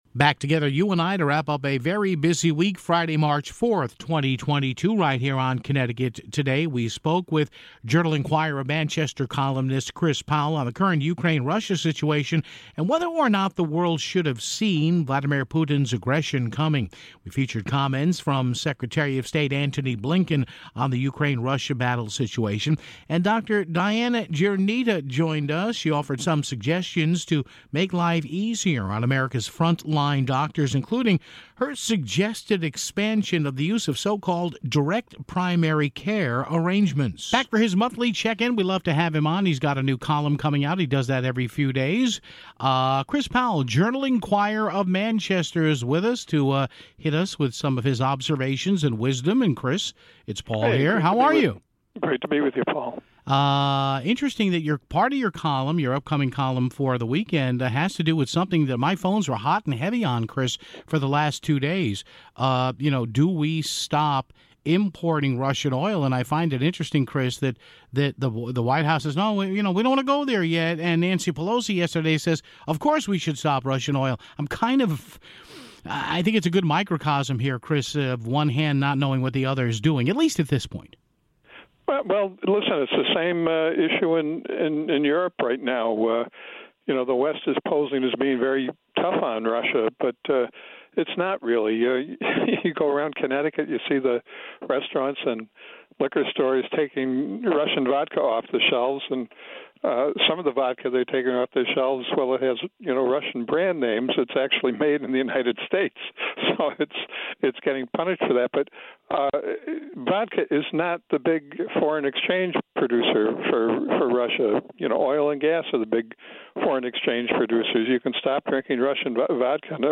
We featured comments from Secretary of State Antony Blinken on the Ukraine-Russia battle situation (15:08).